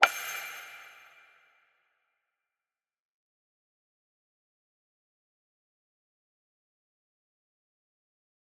parry.wav